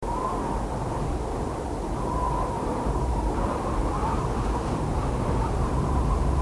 jetwind.mp3